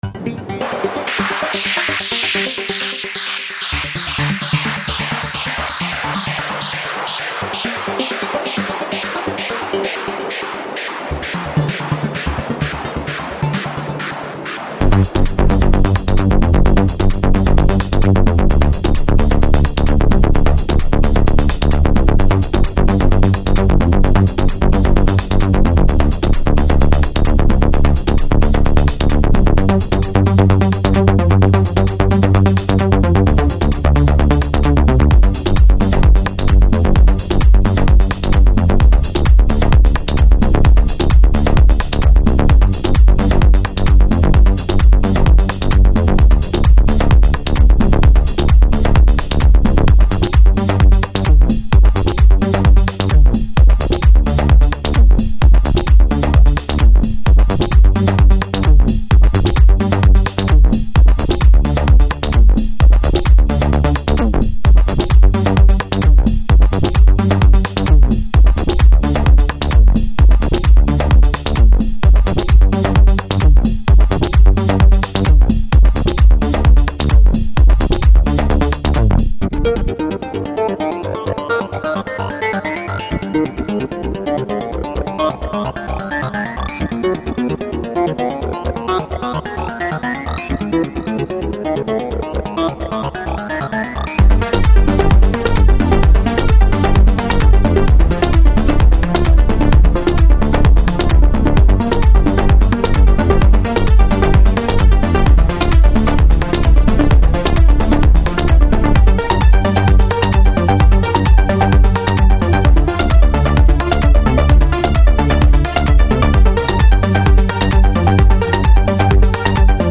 Скачать Минус
Стиль: House